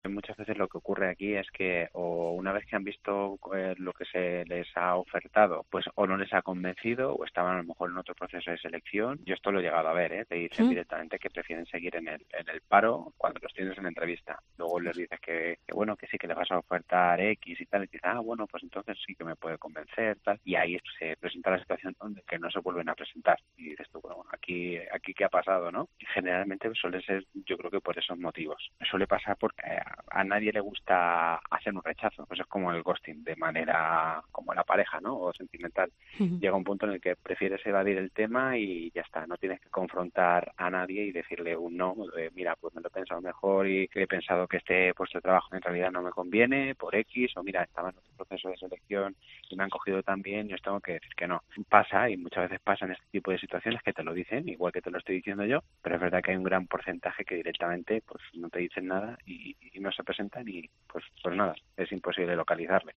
psicólogo experto en RRHH